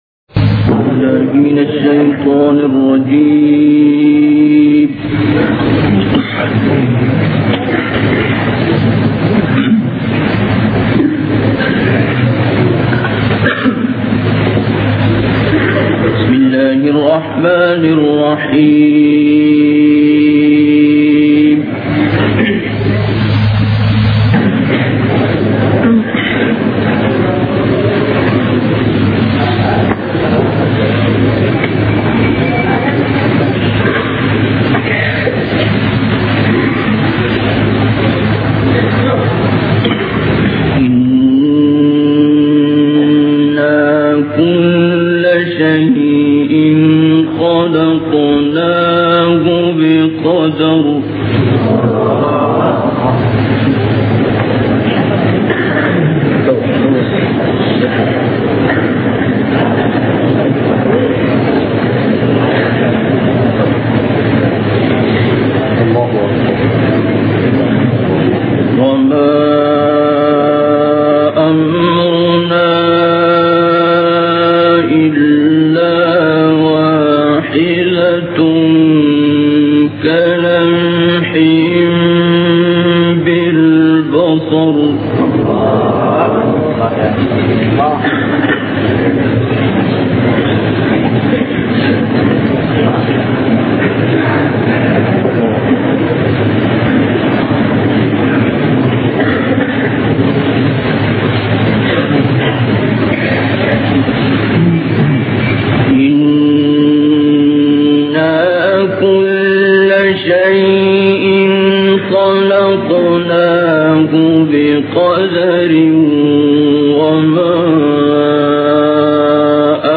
Quran recitations
One of the very rare concerts by Sheikh Abdul Basit Abdul Samad in Malaysia